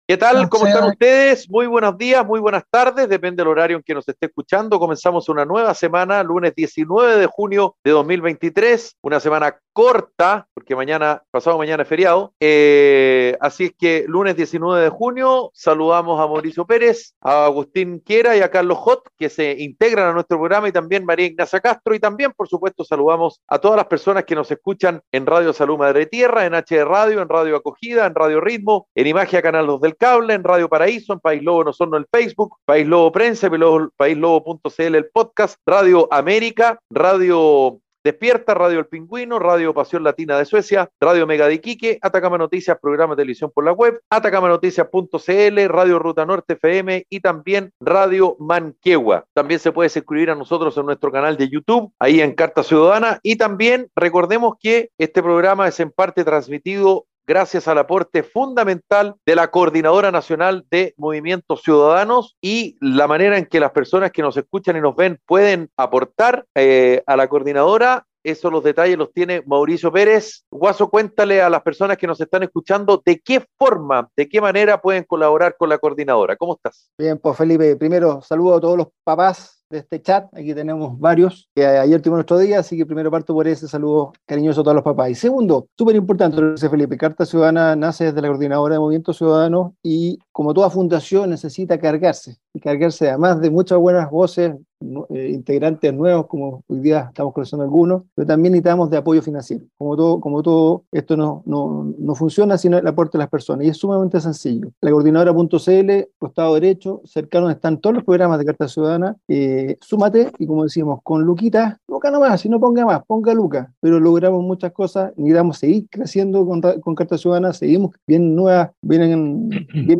Un programa radial de conversación y análisis sobre la actualidad nacional e internacional.